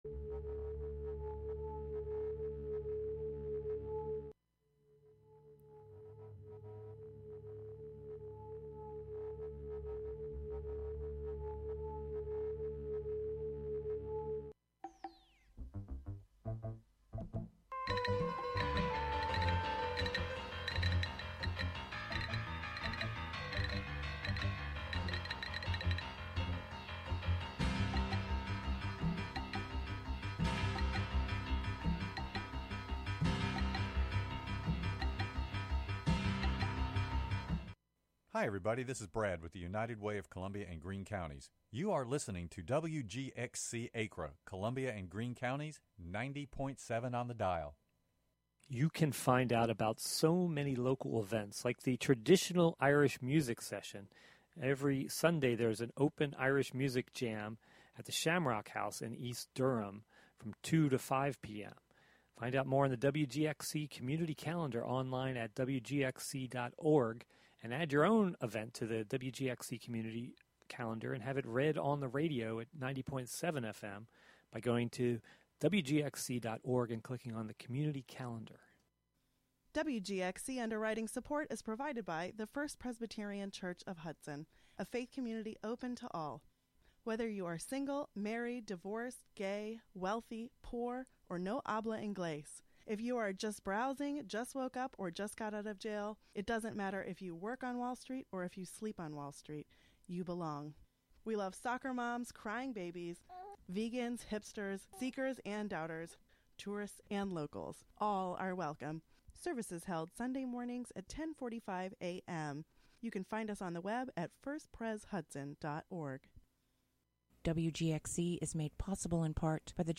And on Nov. 9 activists held a die-in at the office of Rep. Paul Tonko. Tune in to the sounds of the evening from WOOC: After an introduction from Jewish Voices for Peace, dozens of people lay in the street under white blankets while the names of just a few of the people killed in Gaza were read.